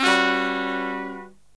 LONG HIT01-R.wav